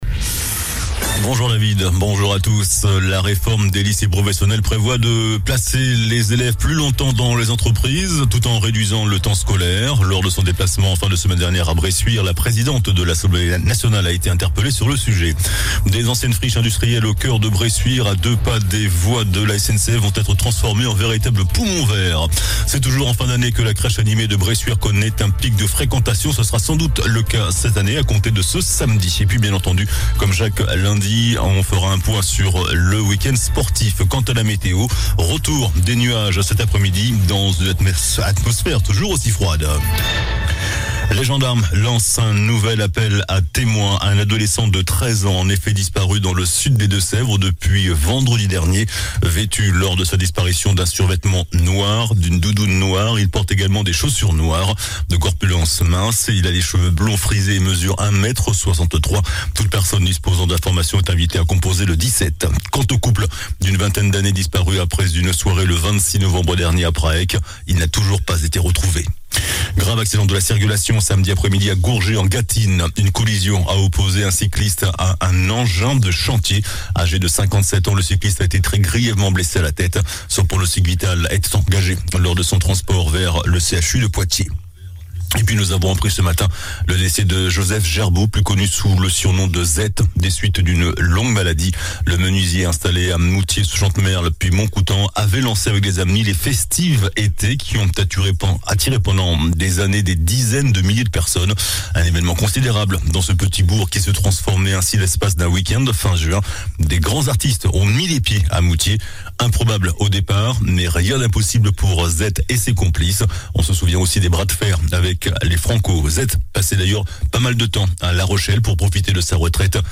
JOURNAL DU LUNDI 12 DECEMBRE ( MIDI )